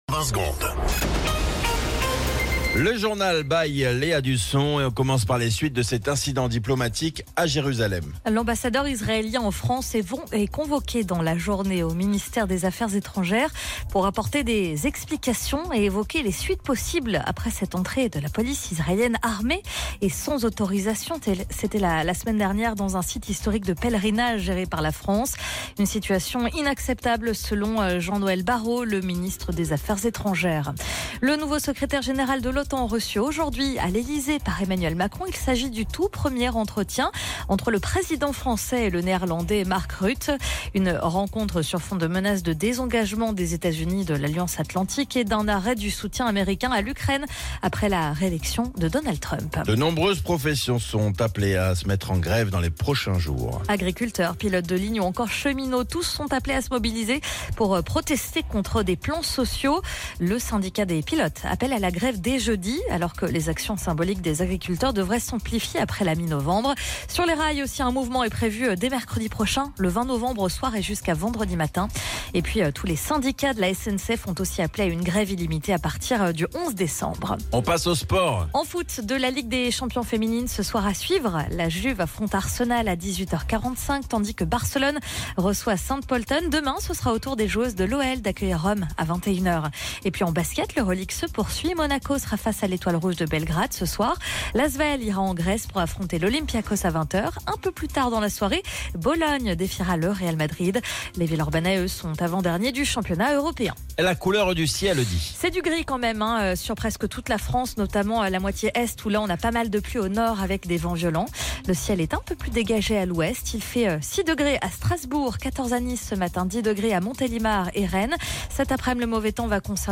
Flash Info National 12 Novembre 2024 Du 12/11/2024 à 07h10 .